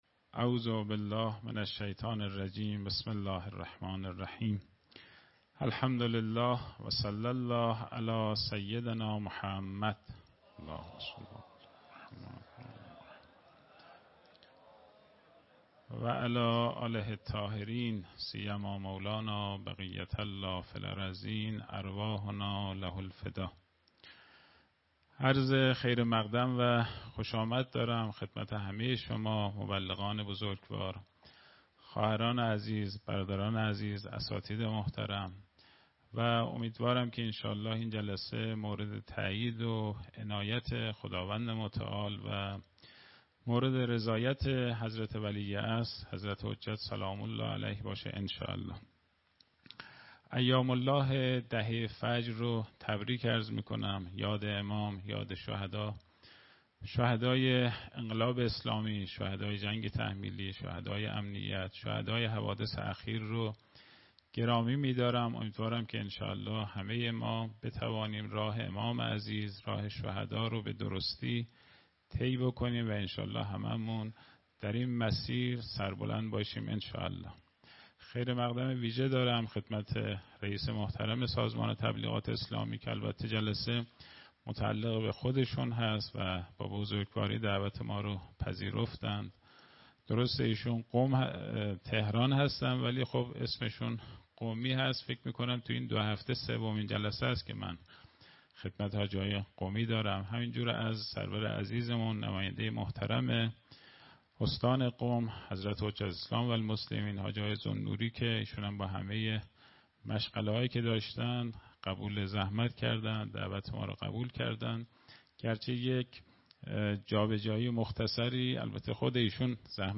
سخنرانی
در سلسله نشست های طلیعه رمضان 1447ه.ق